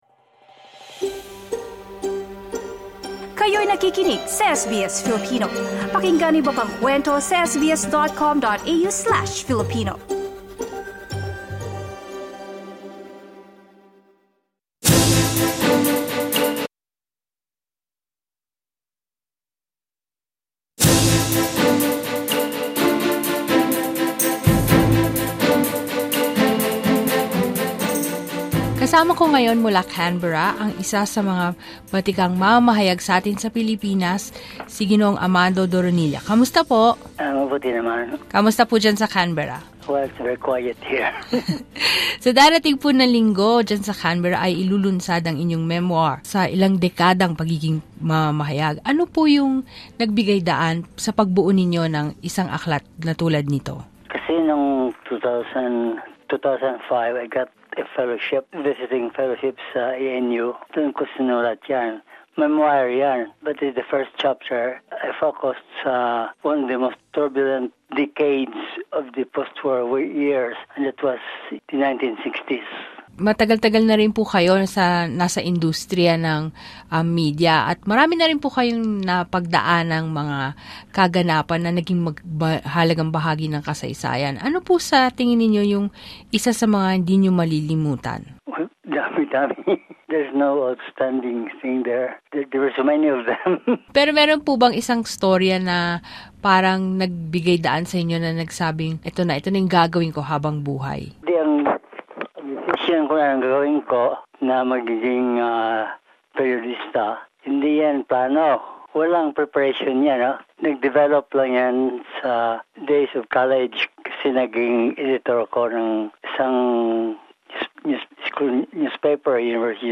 In a rare interview, the late journalist shared how then Prime Minister Gough Whitlam intervened and facilitated his relocation to Australia during the Martial Law Regime.